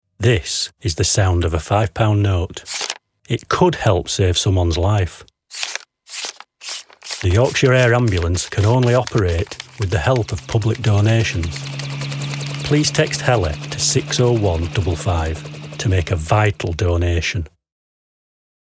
Voice Over Projects